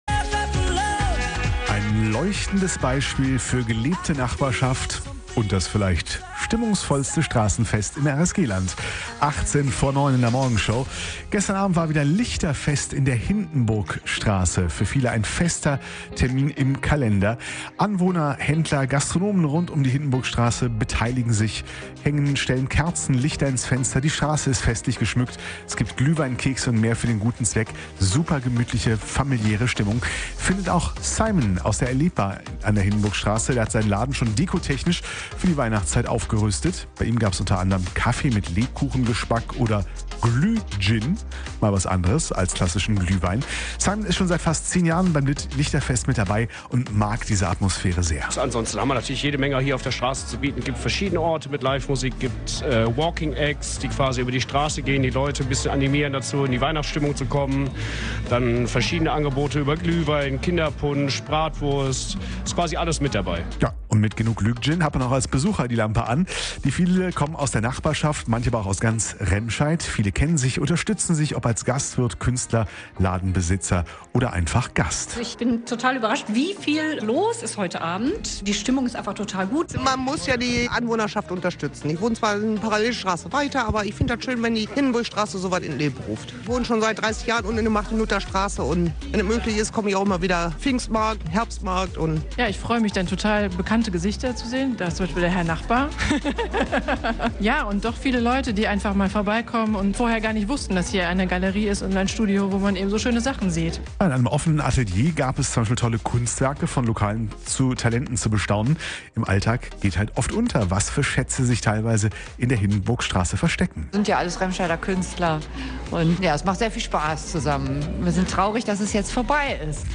Lichterfest in der Hindenburgstraße in Remscheid